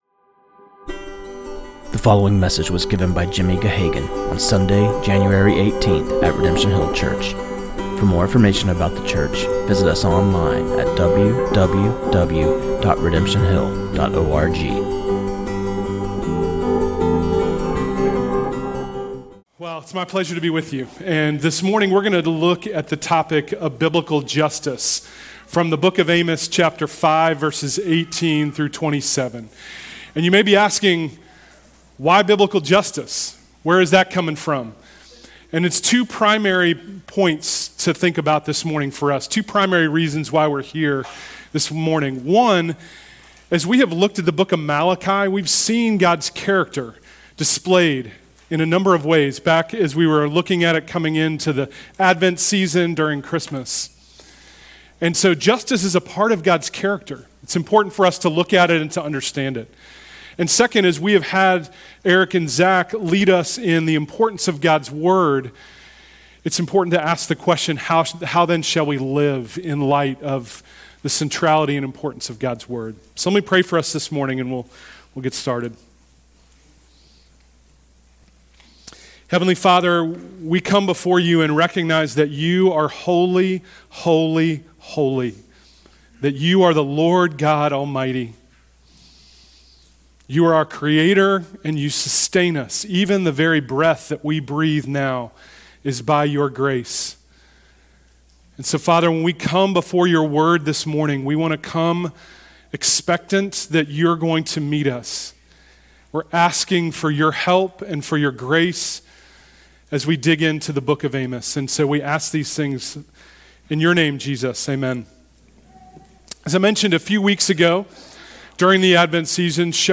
This sermon on Amos 5:18-27